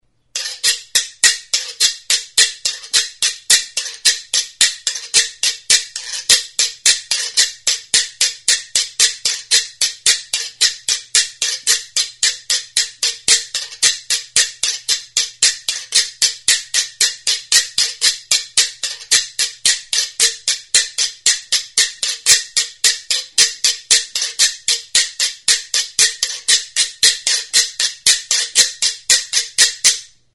Music instrumentsGUIRU; GUIRO; GÜIRO
Idiophones -> Scraped
Recorded with this music instrument.
CANE; BAMBOO